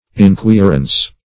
inquirance - definition of inquirance - synonyms, pronunciation, spelling from Free Dictionary Search Result for " inquirance" : The Collaborative International Dictionary of English v.0.48: Inquirance \In*quir"ance\, n. Inquiry.